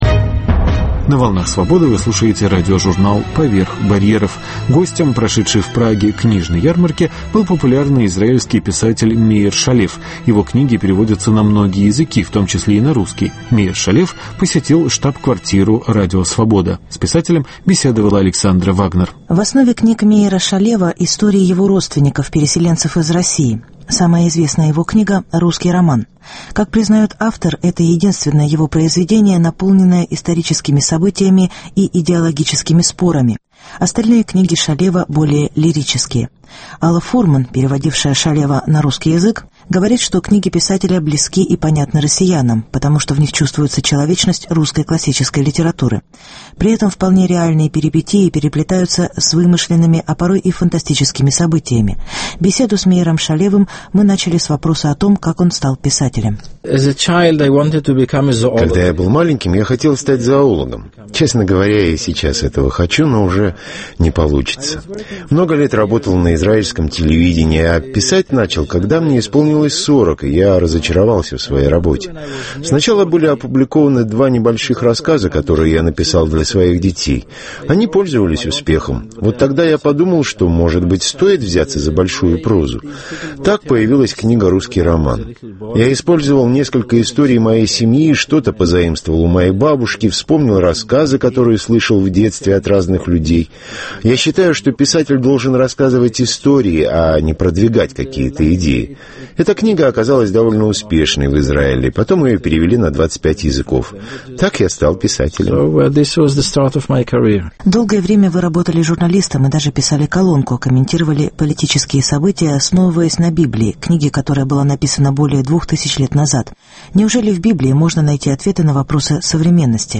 Беседа с писателем Меиром Шалевом.